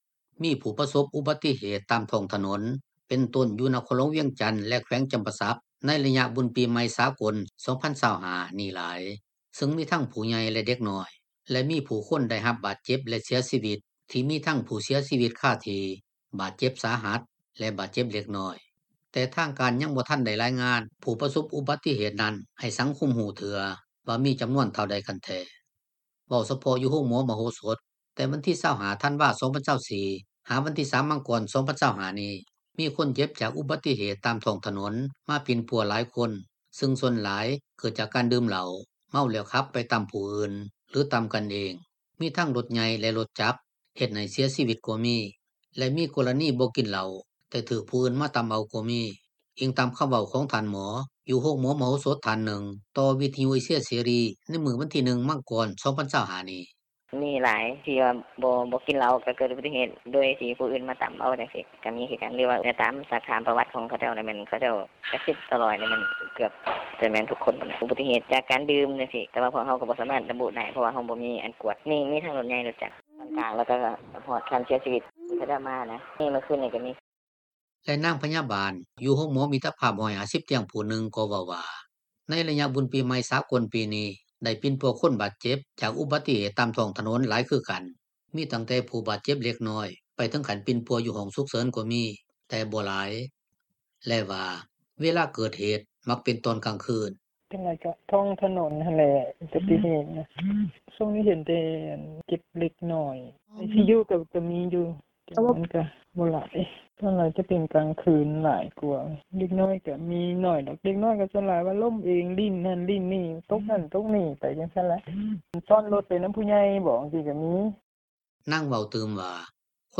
ແລະ ນາງພະຍາບານ ຢູ່ໂຮງໝໍມິດຕະພາບ 150 ຕຽງ ຜູ້ໜຶ່ງ ກໍເວົ້າວ່າ ໃນໄລຍະ ບຸນປີໃໝ່ສາກົນ ປີນີ້, ໄດ້ປິ່ນປົວ ຄົນບາດເຈັບ ຈາກອຸບັດຕິເຫດ ຕາມທ້ອງຖະໜົນ ຫຼາຍຄືກັນ. ມີຕັ້ງແຕ່ ຜູ້ບາດເຈັບເລັກນ້ອຍ ໄປເຖິງຂັ້ນປິ່ນປົວ ຢູ່ຫ້ອງສຸກເສີນ ກໍມີ ແຕ່ບໍ່ຫຼາຍ ແລະ ວ່າ ເວລາເກີດເຫດ ມັກເປັນຕອນກາງຄືນ.
ກ່ຽວກັບເລື່ອງທີ່ວ່ານີ້, ເຈົ້າໜ້າທີ່ ອາສາສະໝັກກູ້ໄພ ຢູ່ນະຄອນຫຼວງວຽງຈັນ ທ່ານໜຶ່ງ ເວົ້າສັ້ນໆວ່າ ໃນໄລຍະ ບຸນປີໃໝ່ສາກົນ ປີນີ້, ຊາວນະຄອນຫຼວງວຽງຈັນ ພາກັນສະຫຼອງ ຢ່າງມ່ວນຊື່ນ, ແຕ່ມີອຸບັດຕິເຫດ ລົດຕໍາກັນຫຼາຍໂພດ, ຫຼາຍກວ່າປີທີ່ຜ່ານມາ, ແຕ່ກໍຍັງ ບໍ່ທັນມີການສະຫຼຸບ.